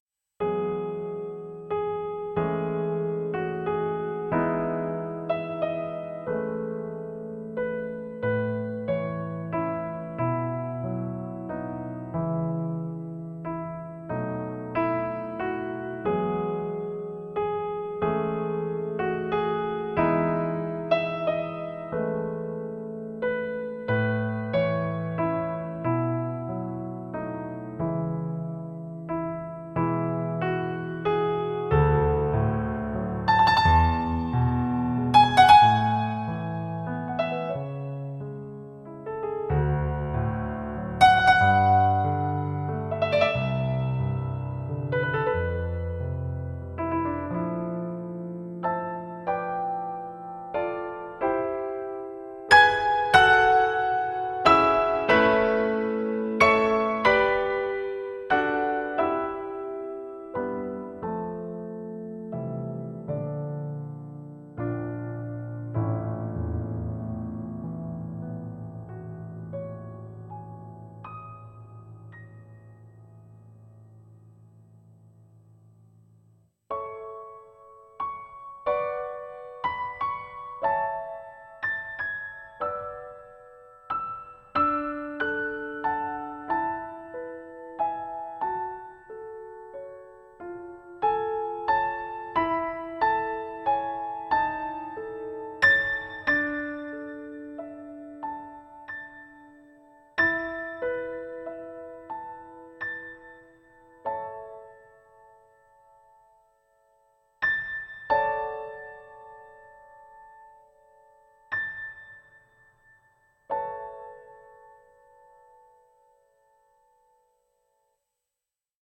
ピアノソロ